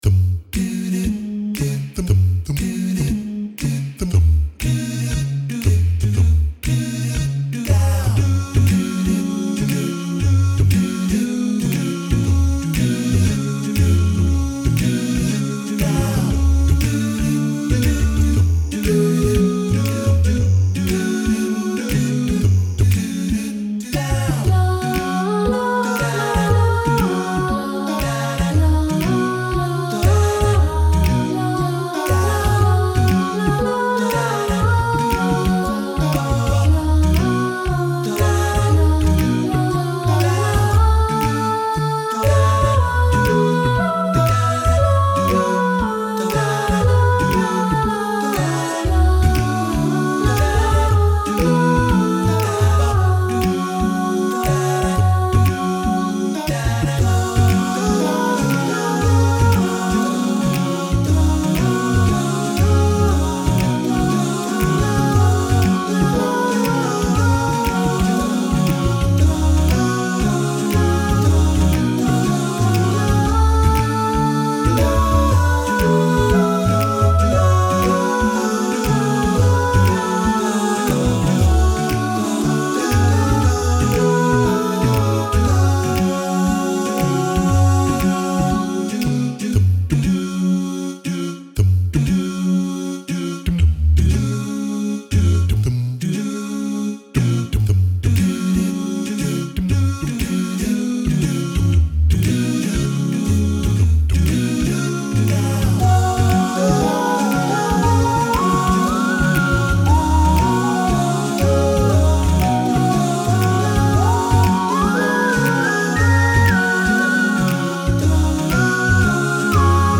Very creative mix.